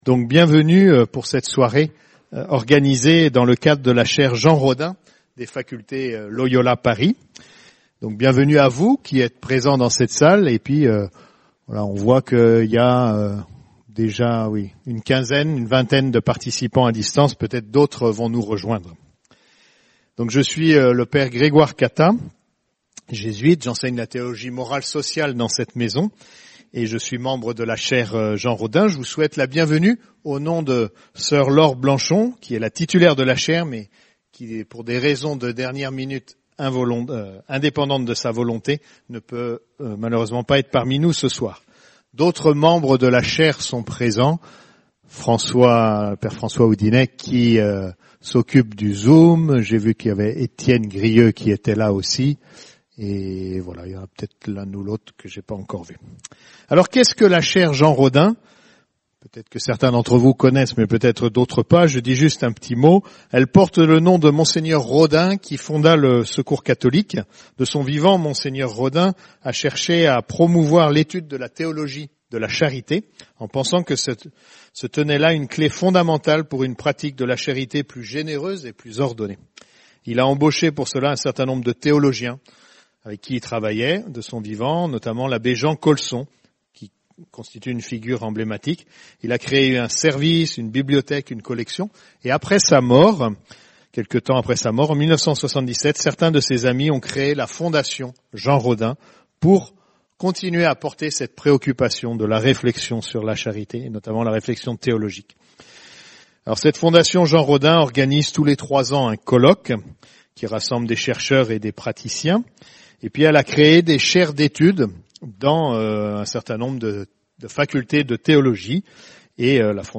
Soirée de la Chaire Jean RODHAIN